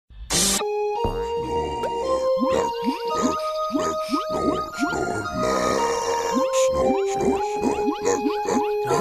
Play, download and share Snoorlax original sound button!!!!
snorlax-wakeup.mp3